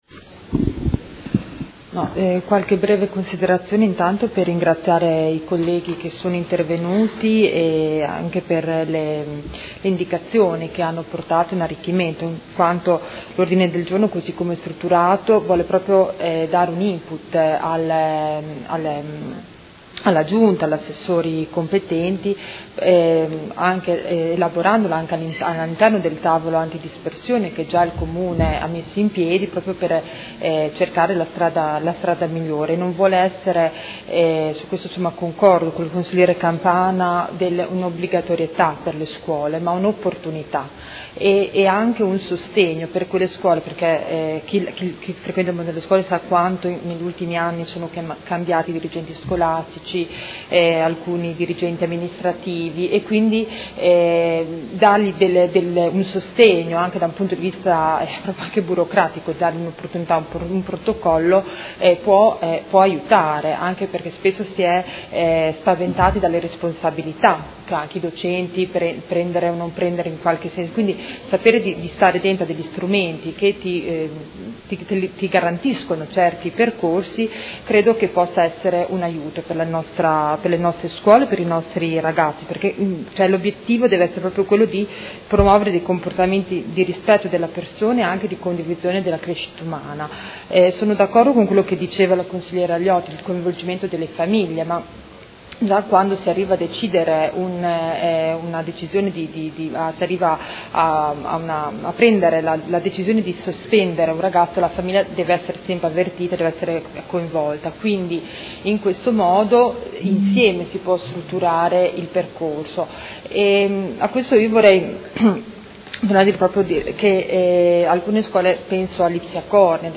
Grazia Baracchi — Sito Audio Consiglio Comunale
Ordine del Giorno presentato dai Consiglieri Baracchi, Stella, Arletti, Pacchioni, Morini, Malferrari, Poggi, Venturelli, Forghieri, Carpentieri, De Lillo, Trande e Fasano (P.D.) avente per oggetto: Istituzione di un protocollo per i percorsi formativi alternativi alle sanzioni disciplinari scolastiche. Replica